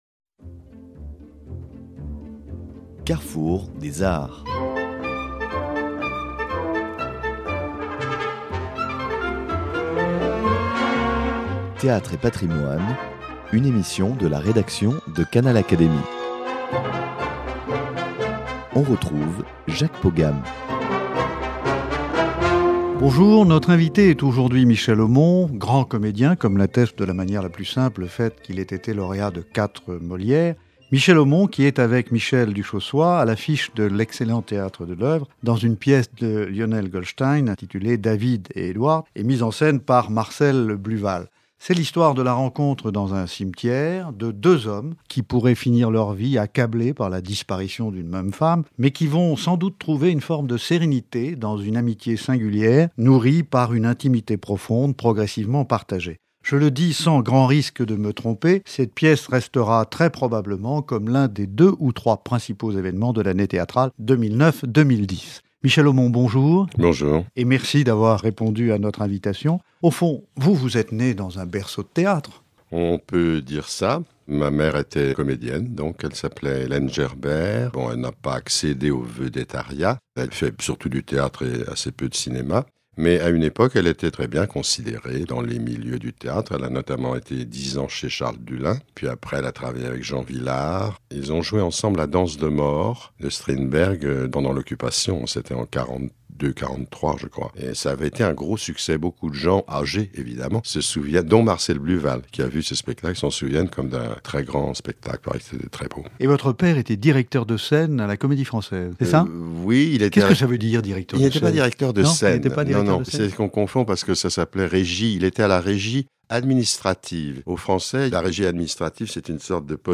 Grand comédien et lauréat de quatre Molières, Michel Aumont est à l’affiche du Théâtre de l’Œuvre, dans David et Edward, une pièce de Lionel Goldstein, mise en scène par Marcel Bluwal. Il est venu nous parler non seulement de cette pièce, qu’il interprète aux côtés de Michel Duchaussoy, et qui s’annonce déjà comme un des plus grands évènements de l’année théâtrale 2010 mais aussi de toute sa carrière, intense et riche.